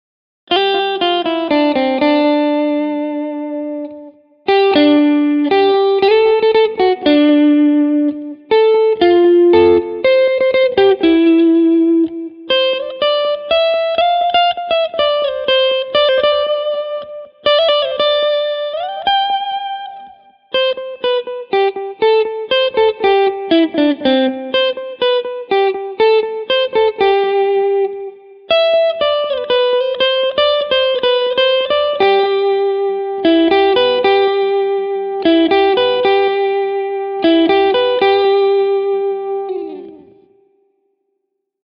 Digitaalisen Boss BC-2:n soundi on ällistyttävän lähellä legendaarista putkikäyttöistä esikuvaansa.
Puhtaissa soundeissa on se alkuperäisestä kombosta tuttu keskialueen kuivuus ja laatikkomaisuus, sekä diskantin maukas ja hieman rouhea purevuus.